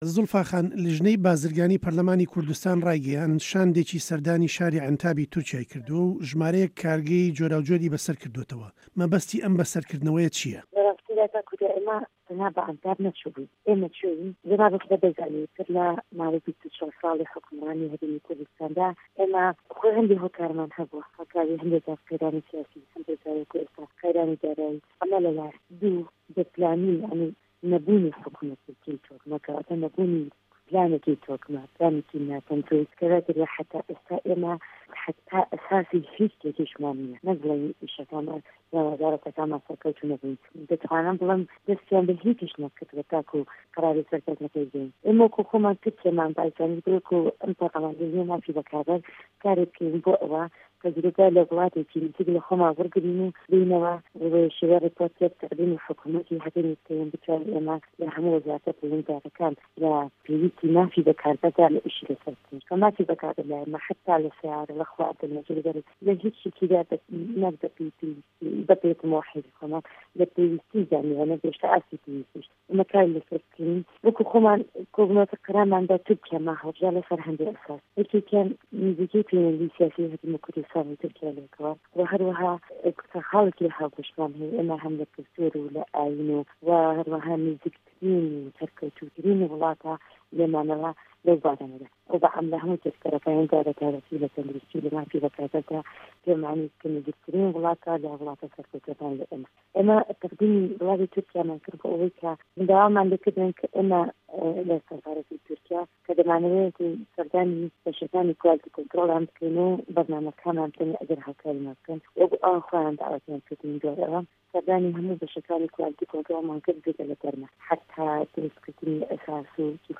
گفتووگۆ له‌گه‌ڵ زوڵفا مه‌حموود